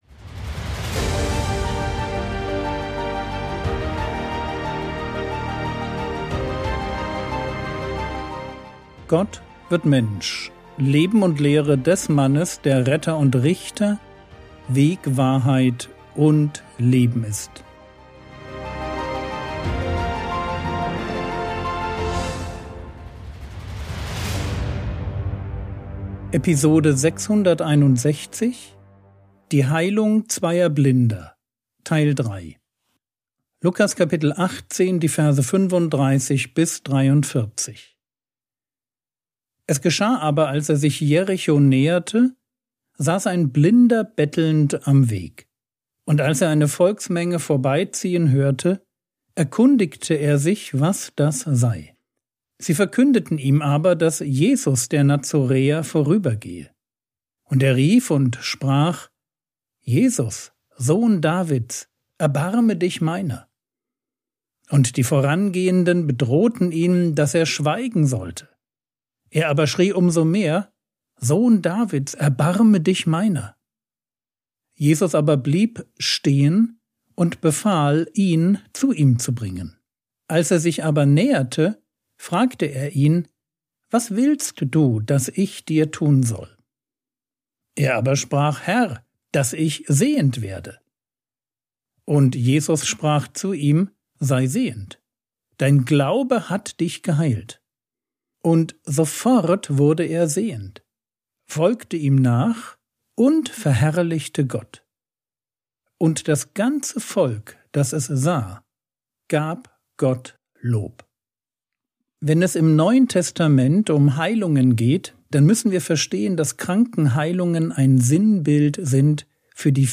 Episode 661 | Jesu Leben und Lehre ~ Frogwords Mini-Predigt Podcast